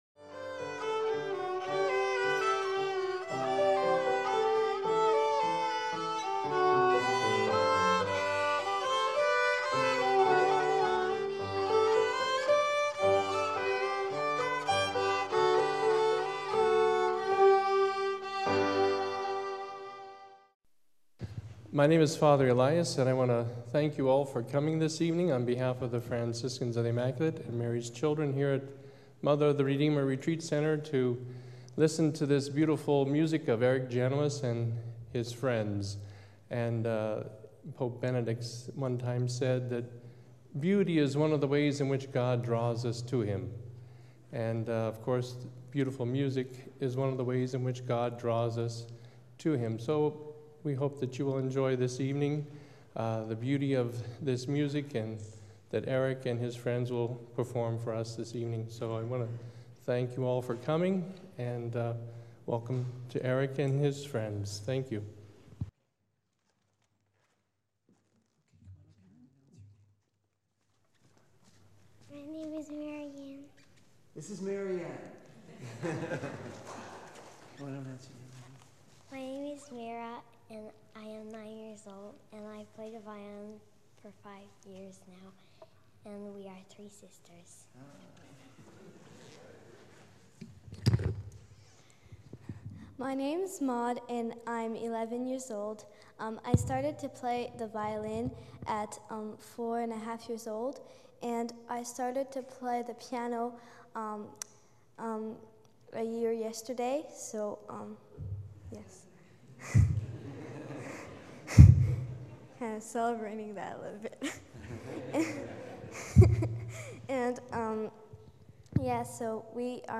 gave the opening performance
violin
piano